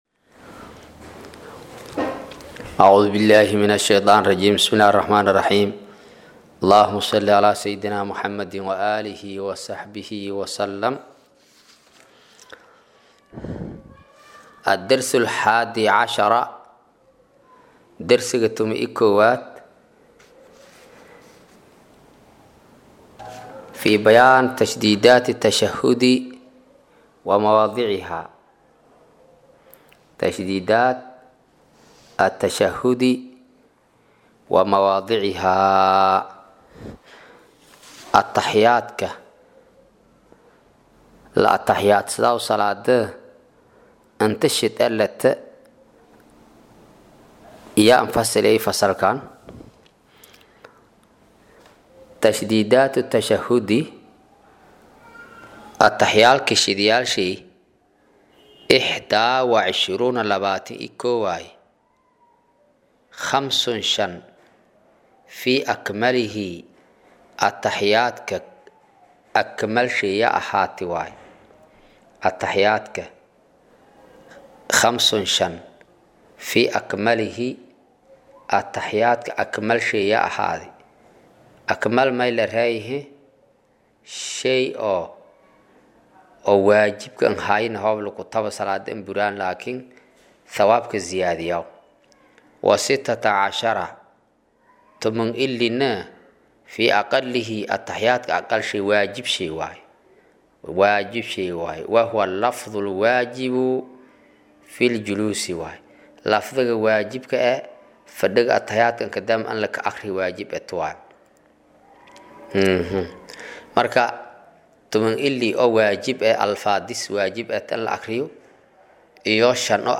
casharka-11-aad-ee-safiinatu-najaa.mp3